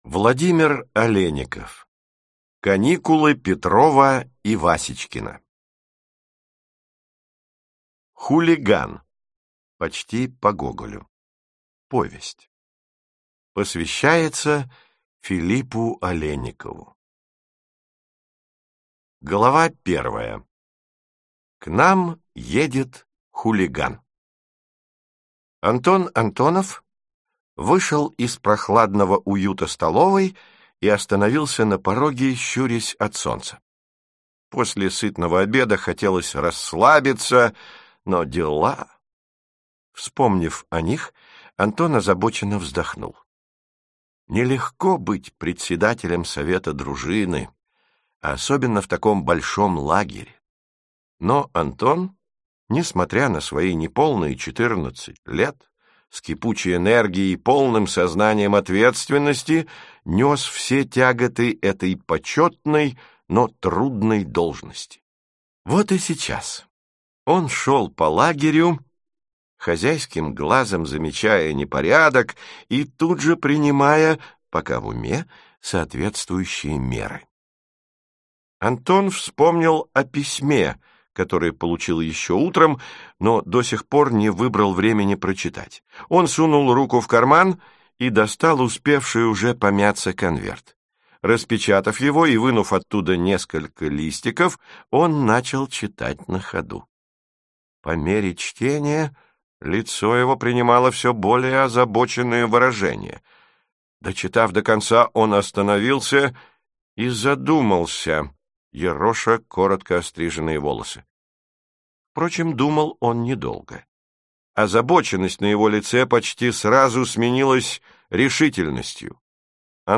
Аудиокнига Каникулы Петрова и Васечкина | Библиотека аудиокниг